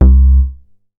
MoogLoRess 002.WAV